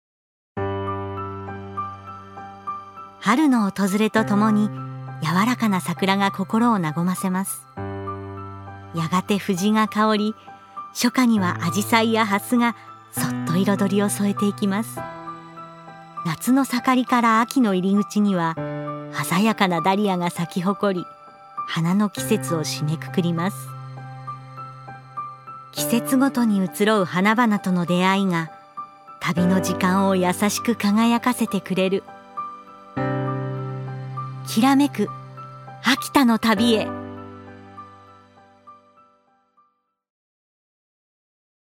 女性タレント
1. ナレーション１